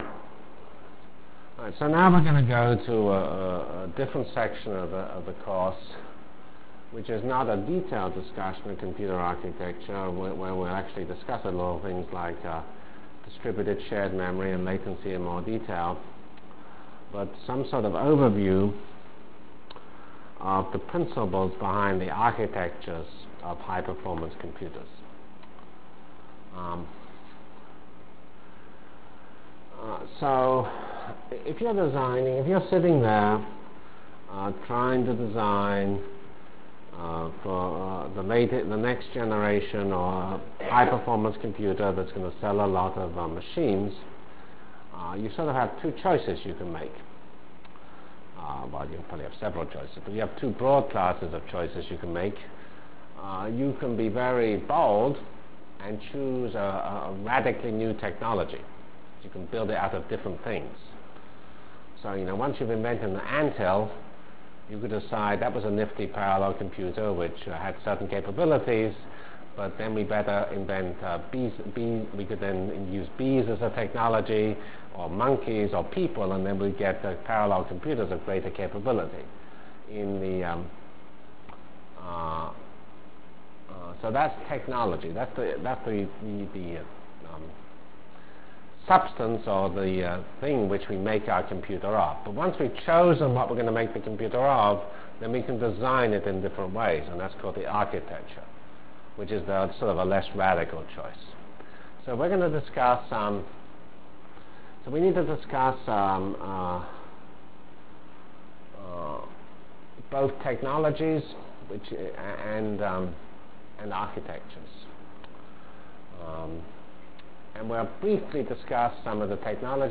From CPS615-Lecture on Performance(end) and Computer Technologies(start) Delivered Lectures of CPS615 Basic Simulation Track for Computational Science -- 5 September 96. by Geoffrey C. Fox *